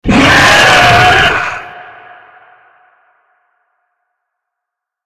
attack_hit_3.ogg